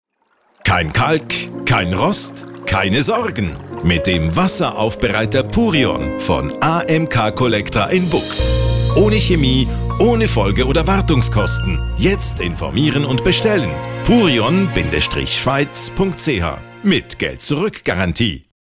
Purion-Radiospot.mp3